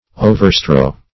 Overstrow \O`ver*strow"\, v. t.